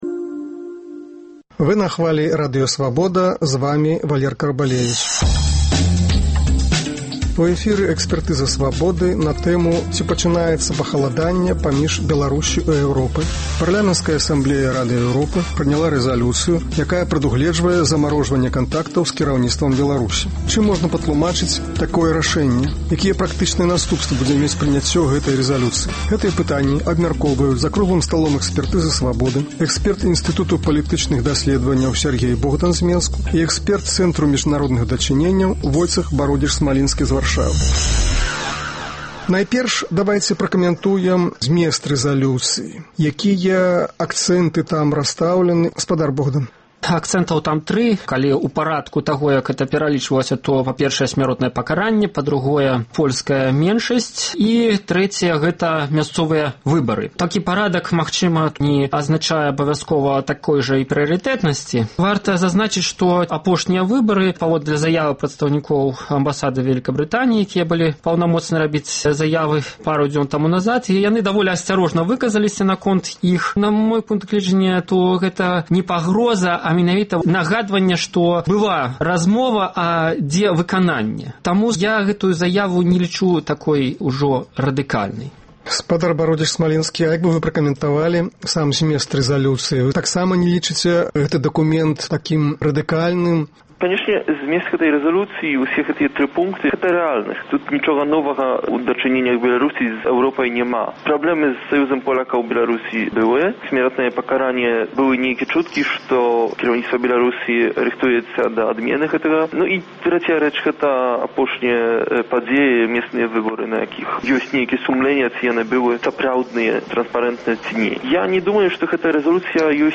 Гэтыя пытаньні абмяркоўваюць за круглым сталом "Экспэртызы "Свабоды”